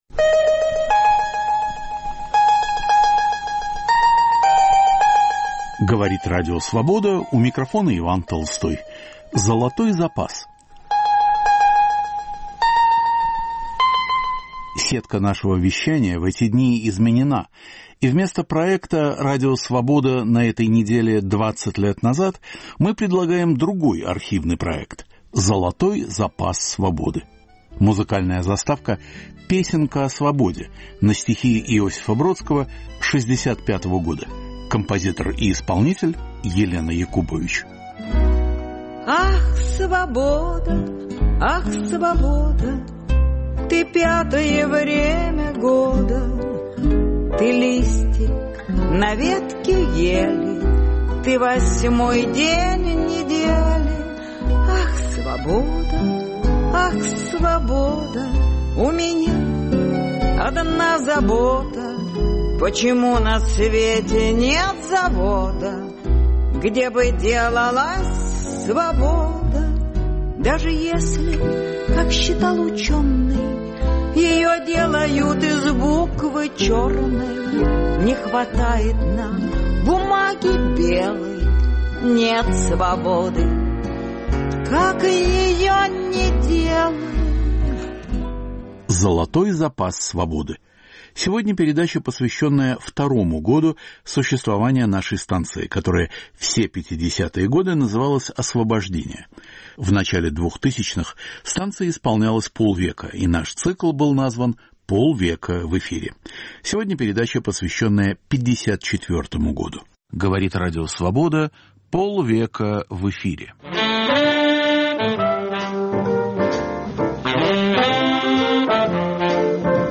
К 50-летию Радио Свобода. Фрагменты передач 1954-го.